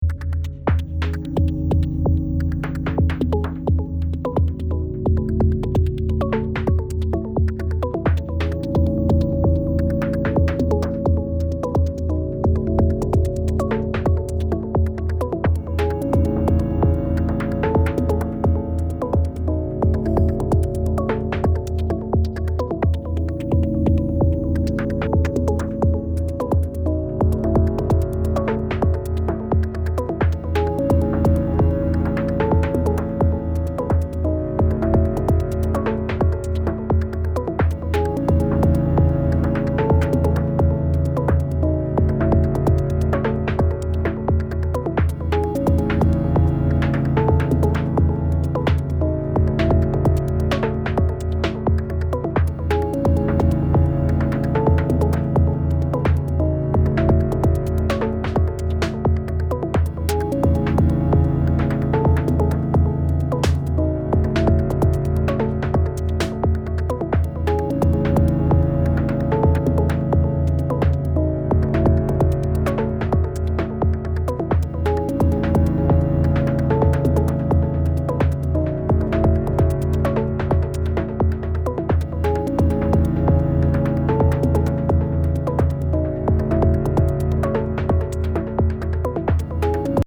Some Machinedrum GND machine sounds for a change.